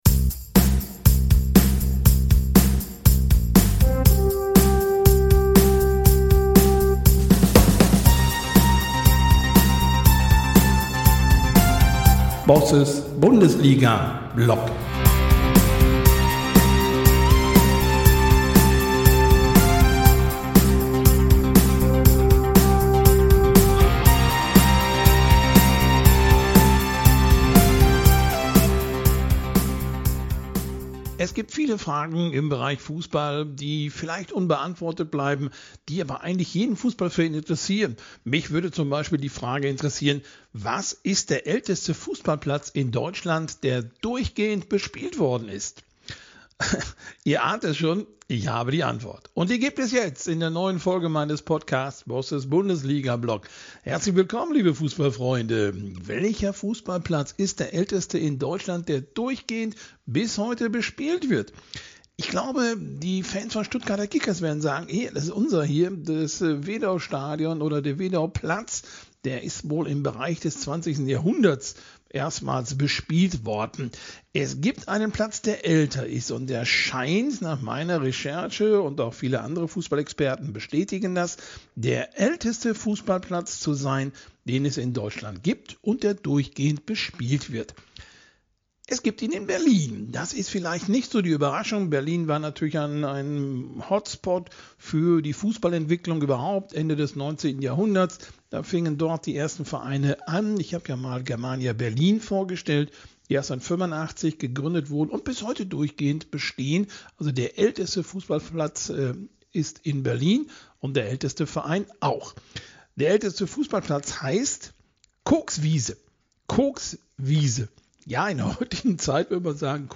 Ein Gespräch für alle, die Fußball nicht nur als Spiel, sondern als Kulturgeschichte ...